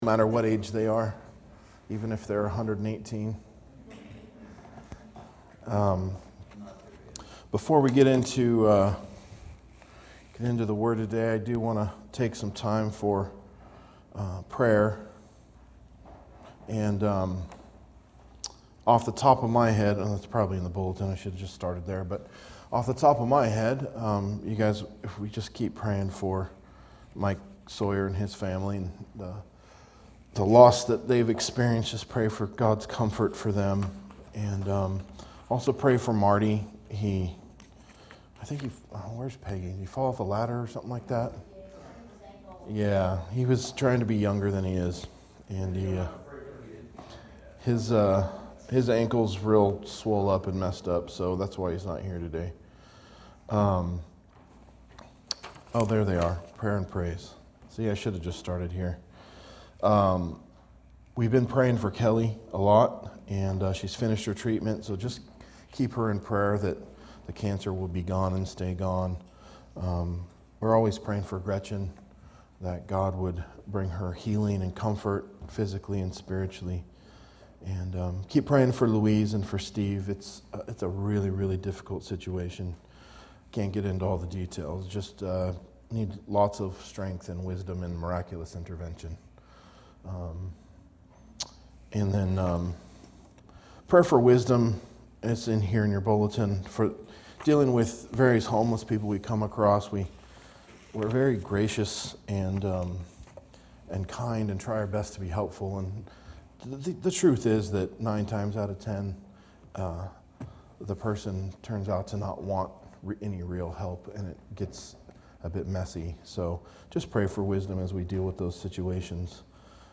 A message from the series "Matthew." Matthew 24:36-51